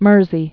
(mûrzē)